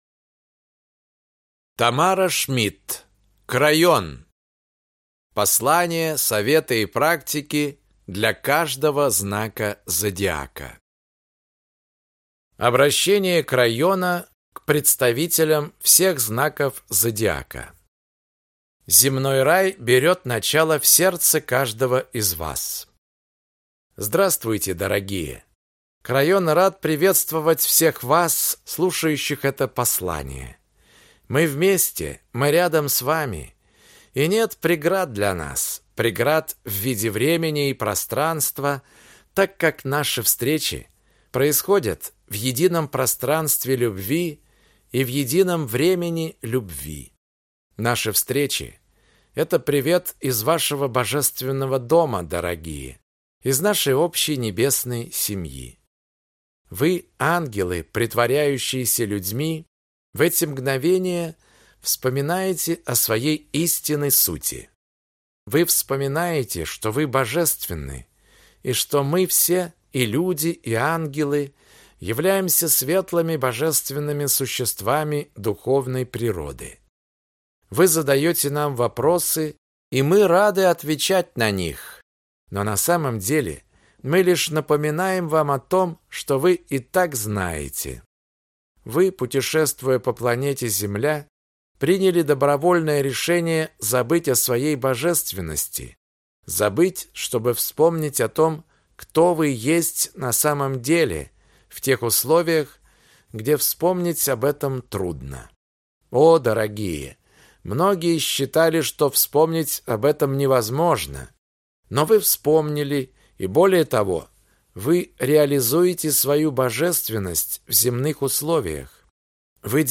Аудиокнига Крайон. Послания, советы и практики для каждого знака Зодиака | Библиотека аудиокниг